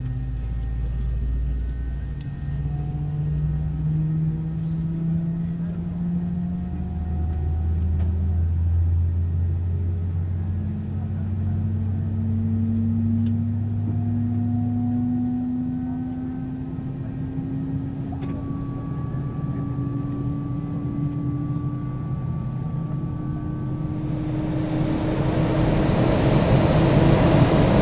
A320_cockpit_startup.wav